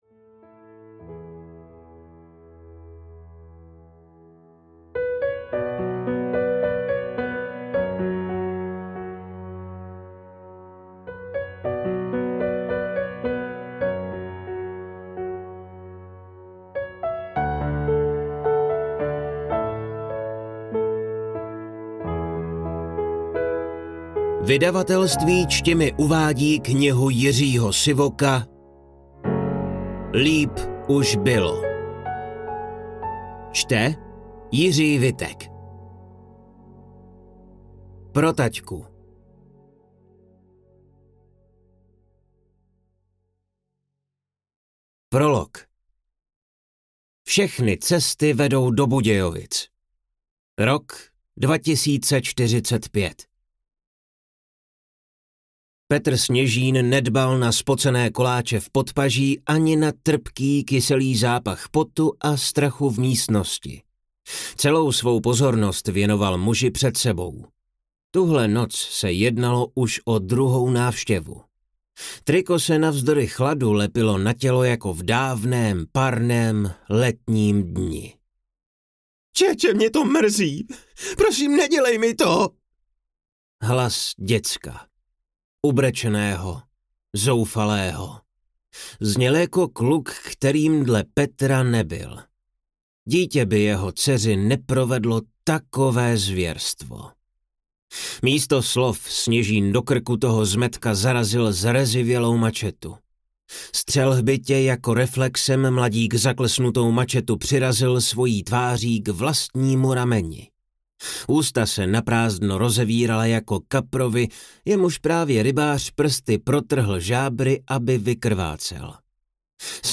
Líp už bylo audiokniha
Ukázka z knihy